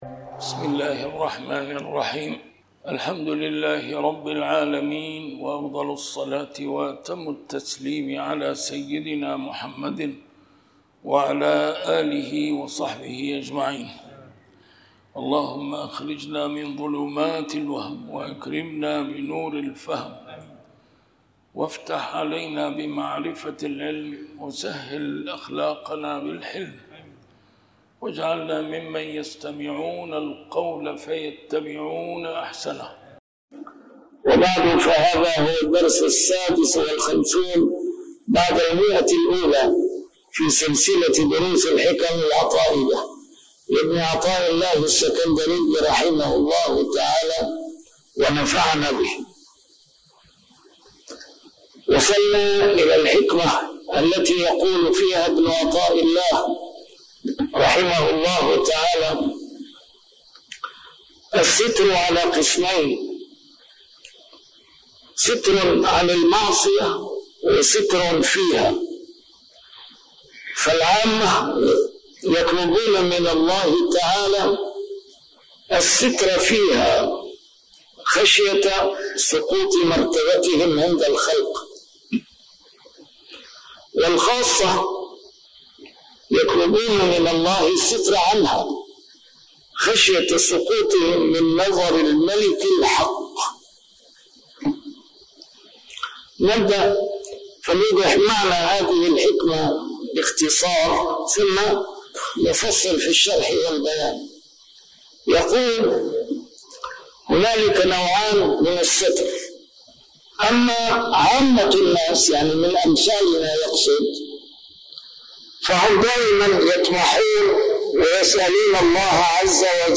A MARTYR SCHOLAR: IMAM MUHAMMAD SAEED RAMADAN AL-BOUTI - الدروس العلمية - شرح الحكم العطائية - الدرس رقم 156 شرح الحكمة 133+134